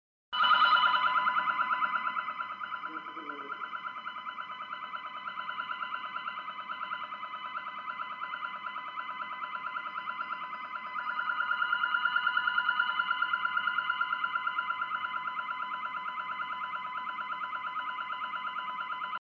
2. Alarma de oficina
Alarma-oficinas-2.mp3